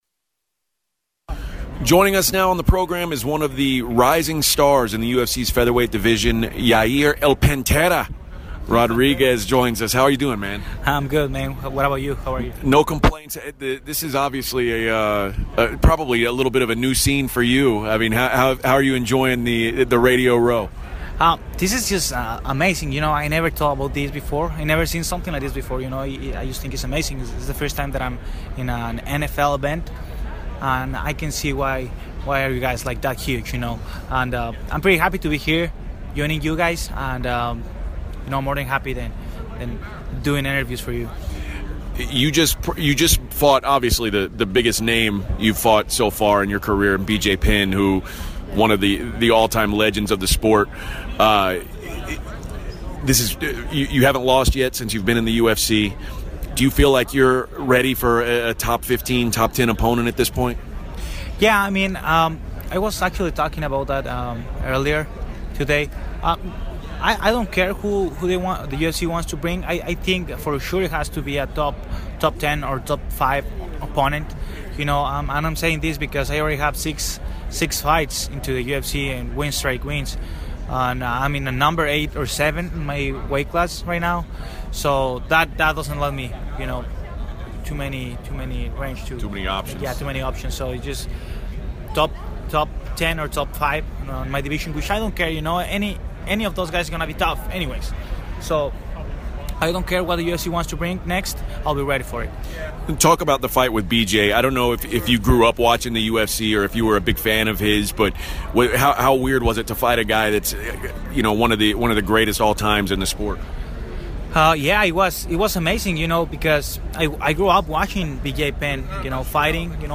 Yair Rodriguez Interview